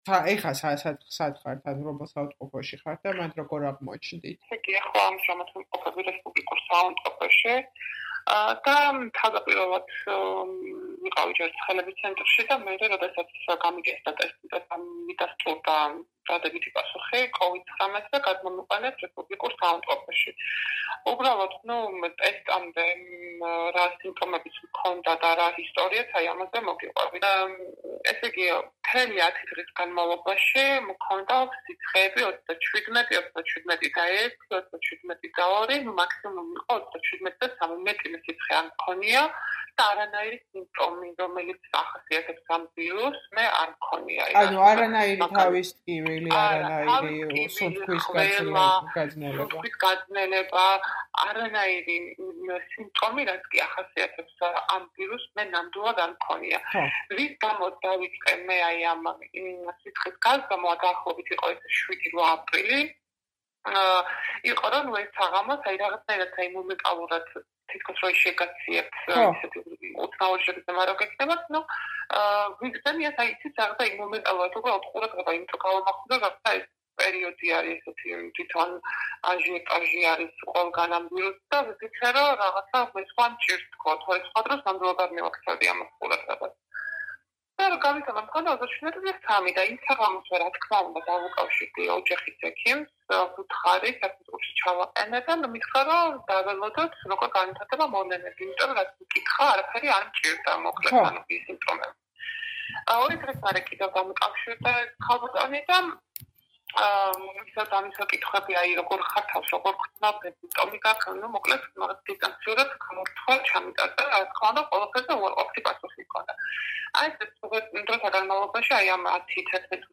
ინტერვიუ ინფიცირებულთან. ორშაბათი, რესპუბლიკური საავადმყოფო